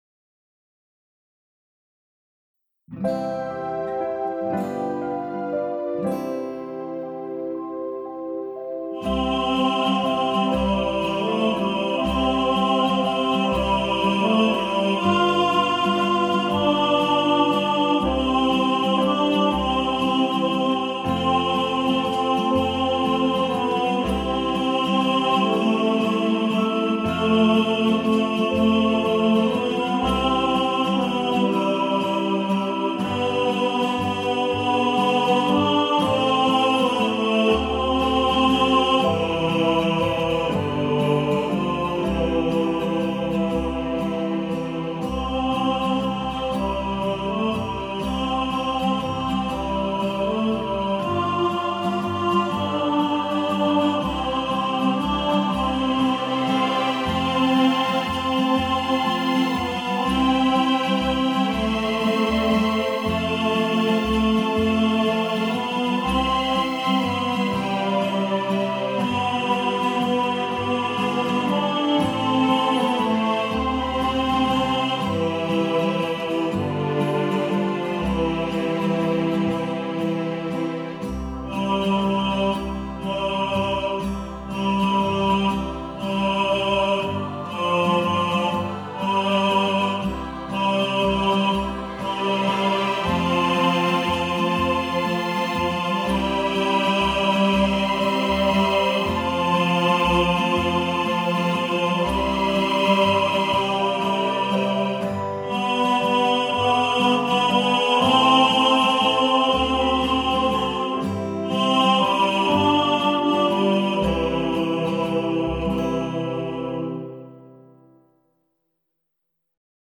Silent-Night-Tenor.mp3